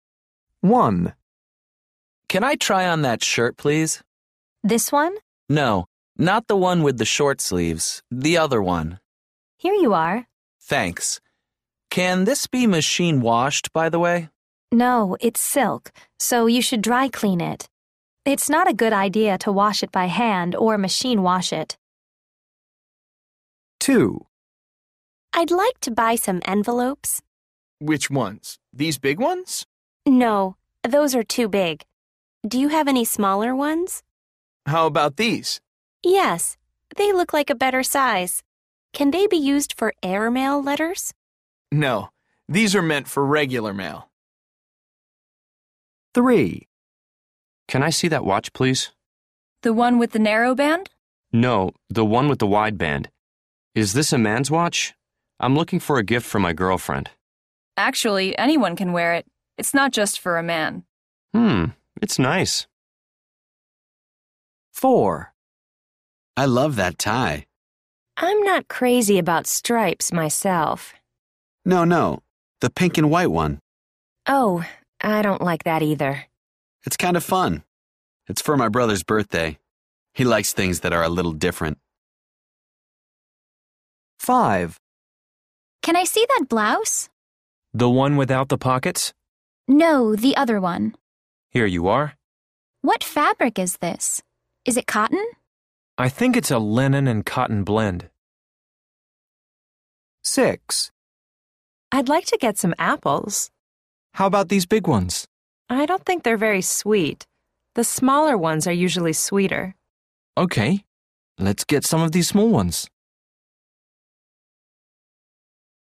A. People are talking about how they spend their free time.